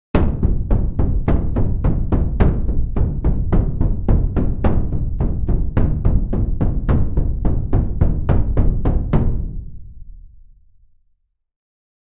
war-drums1